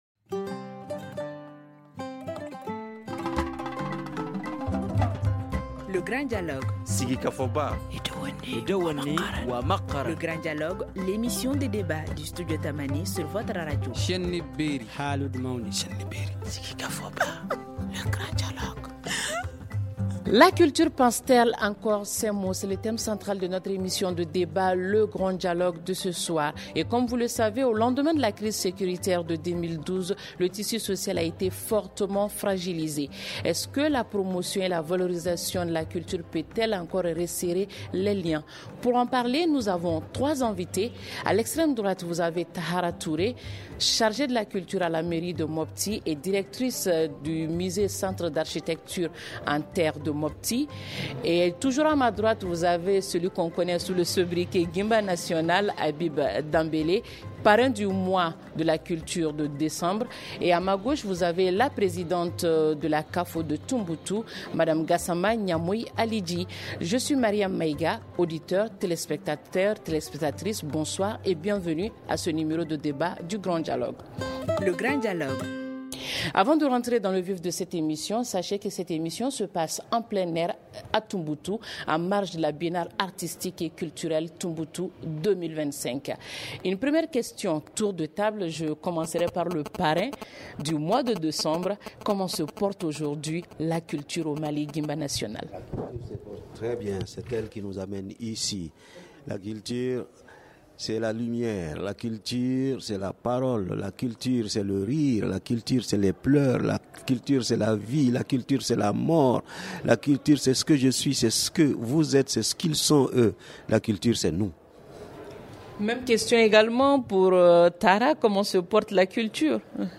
Est-ce que la valorisation des valeurs culturelles peut encore resserrer les liens ? En marge de la biennale artistique et culturelle Tombouctou 2025, Studio Tamani pose le débat sur la question. Pour en parler, nous recevons trois invités :